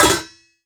metal_lid_movement_impact_03.wav